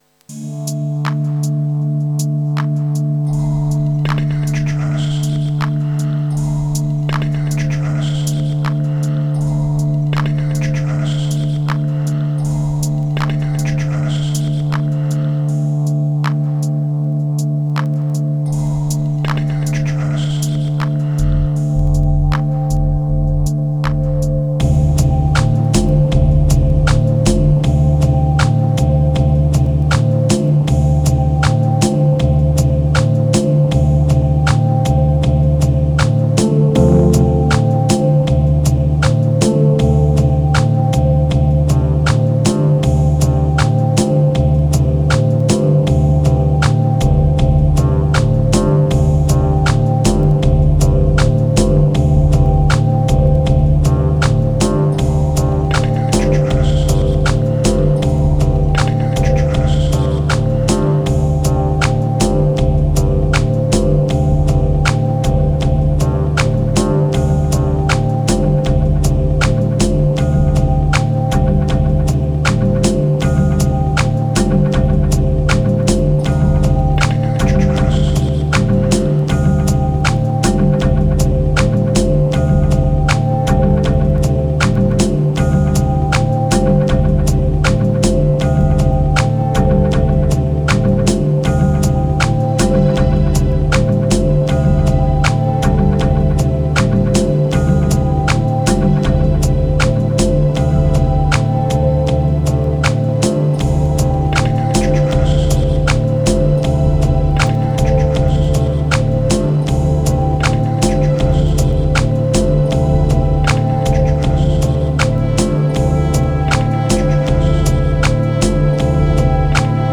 808📈 - 88%🤔 - 79BPM🔊 - 2021-02-19📅 - 1363🌟